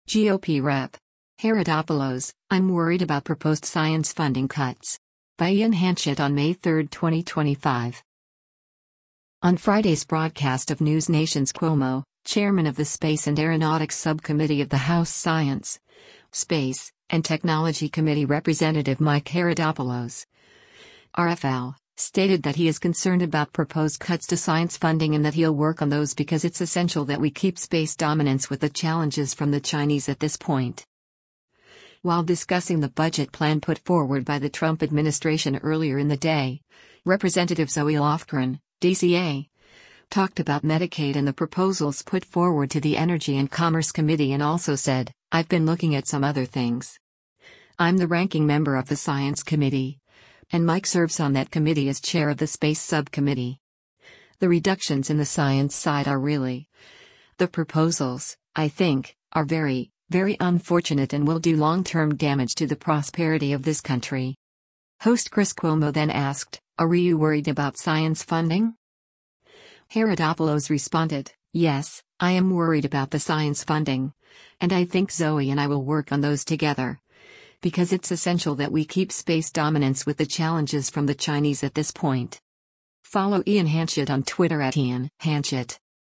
On Friday’s broadcast of NewsNation’s “Cuomo,” Chairman of the Space and Aeronautics Subcommittee of the House Science, Space, and Technology Committee Rep. Mike Haridopolos (R-FL) stated that he is concerned about proposed cuts to science funding and that he’ll work on those “because it’s essential that we keep space dominance with the challenges from the Chinese at this point.”
Host Chris Cuomo then asked, “[A]re you worried about science funding?”